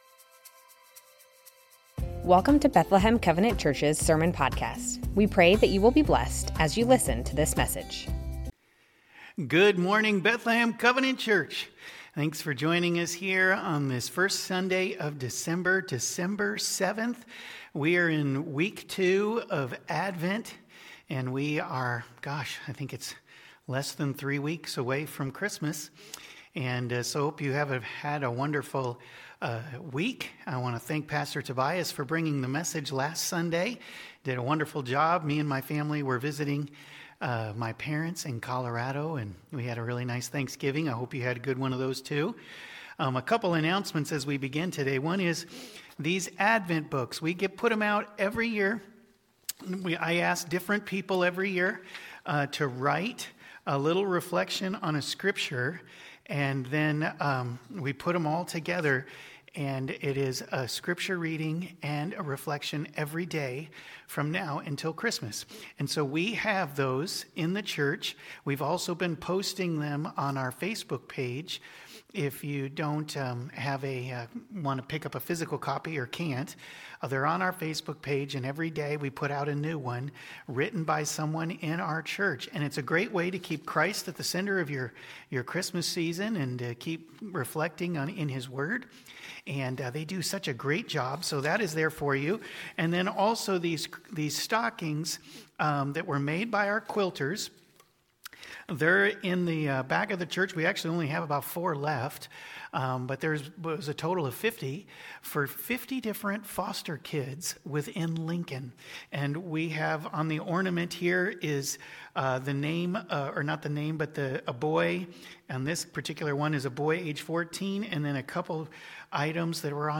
Bethlehem Covenant Church Sermons Advent - Peace Dec 07 2025 | 00:35:15 1x 00:00 / 00:35:15 Subscribe Share Spotify RSS Feed Share Link Embed